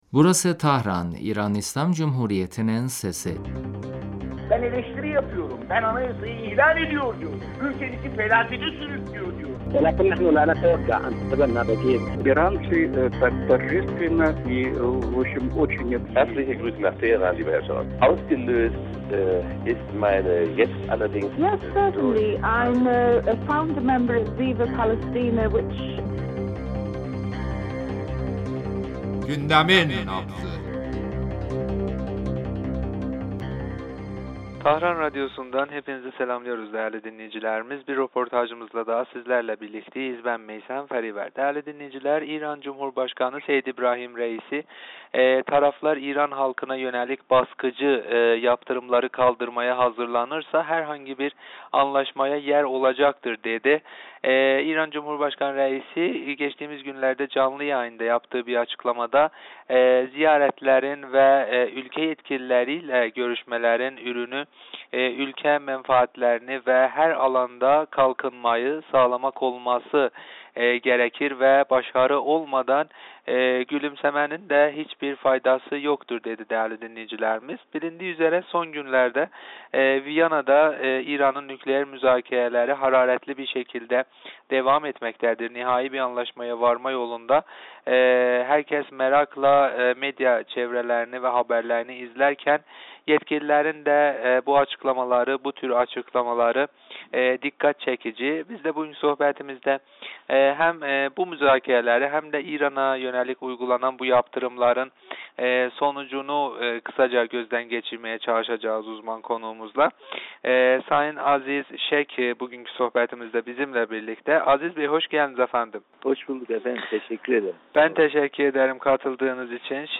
Siyasi meseleler uzmanı